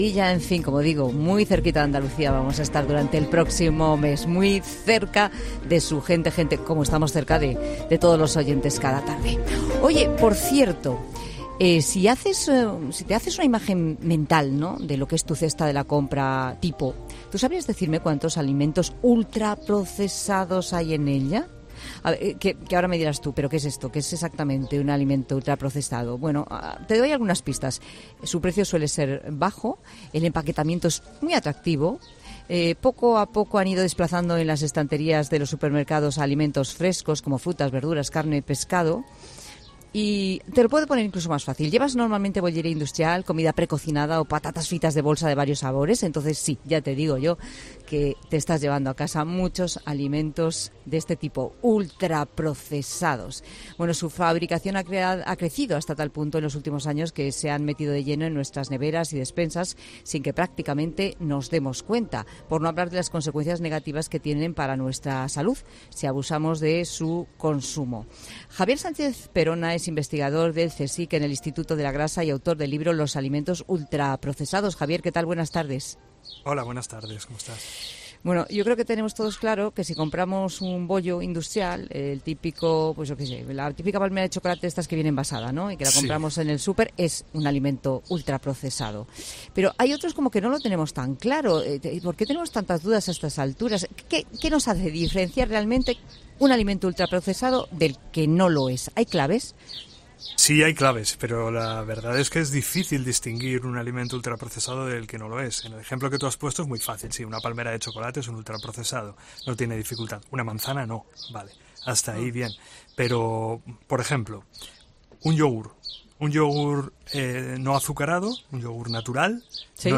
Un experto explica las consecuencias de abusar del consumo de ultraprocesados: "Cada vez hay más estudios"